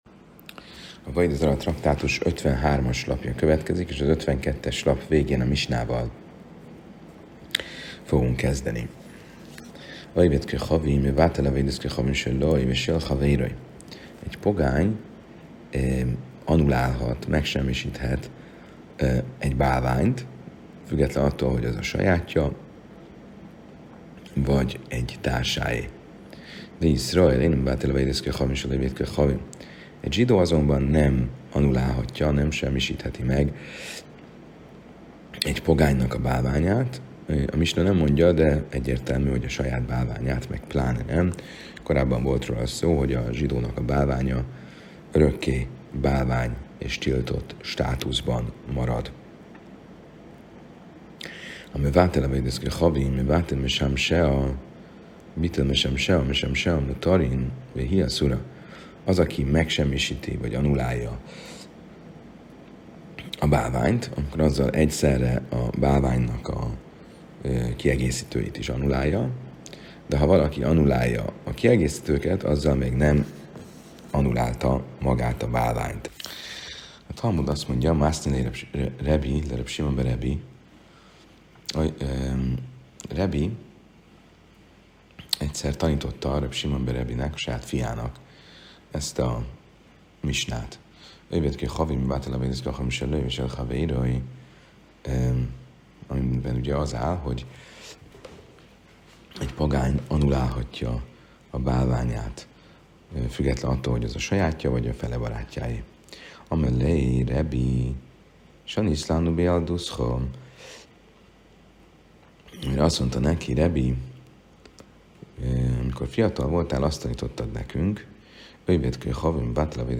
A mai előadásban